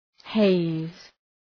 {heız}